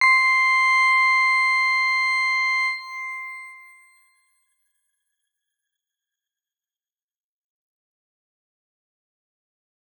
X_Grain-C6-pp.wav